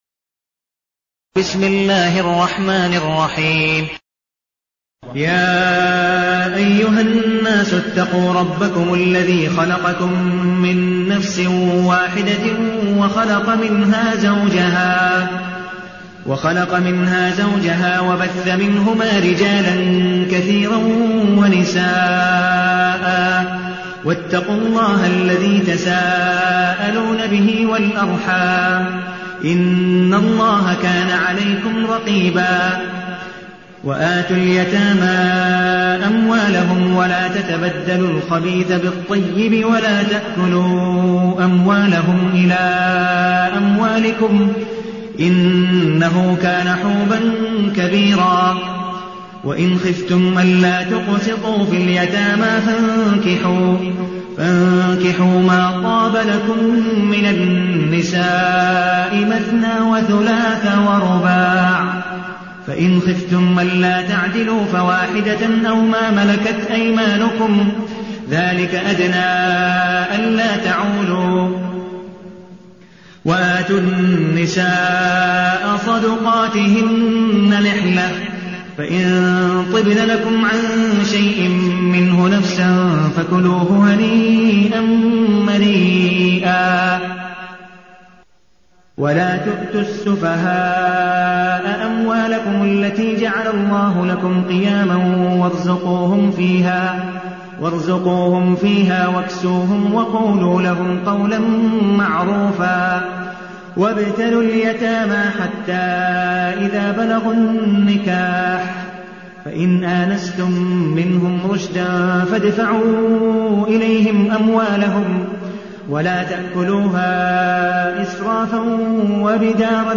المكان: المسجد النبوي الشيخ: عبدالودود بن مقبول حنيف عبدالودود بن مقبول حنيف النساء